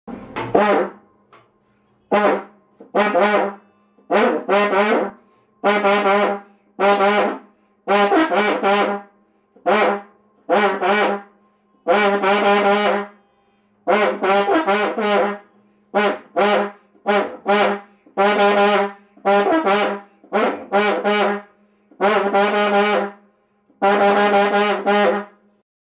Звучание узбекского карная